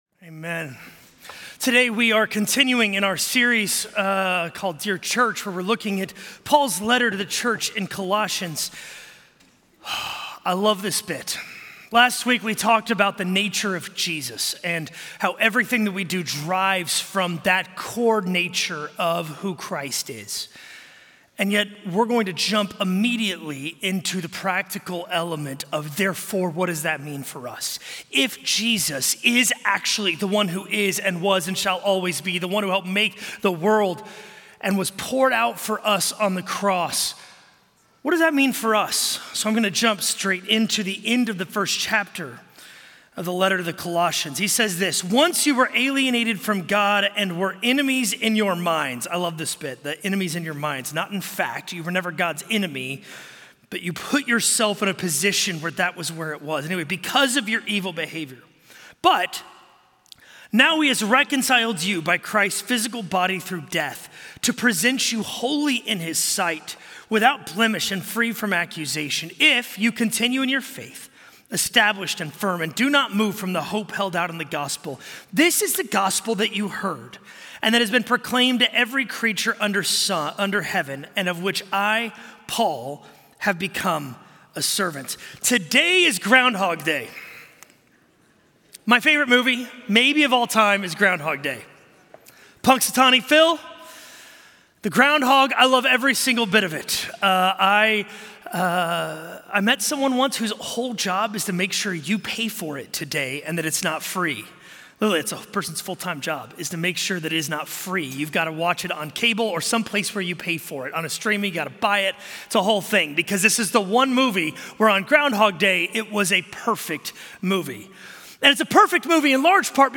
A message from the series "Dear Church."